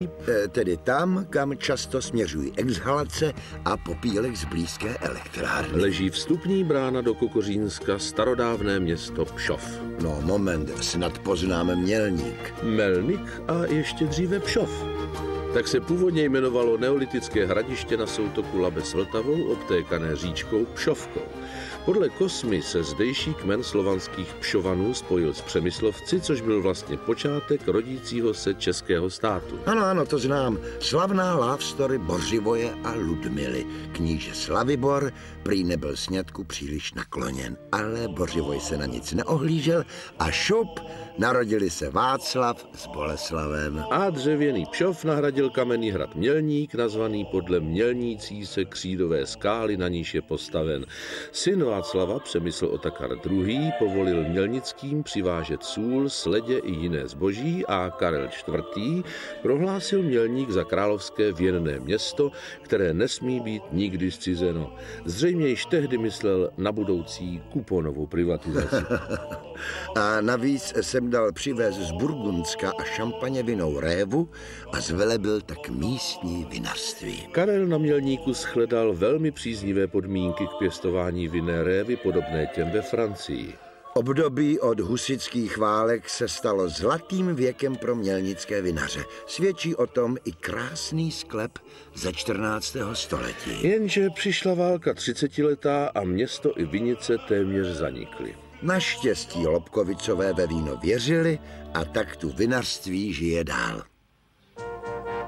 I did a little test recording of a program on a public tv station using my DVR.
btw, the mp3 you posted is also jerky, or is it just me?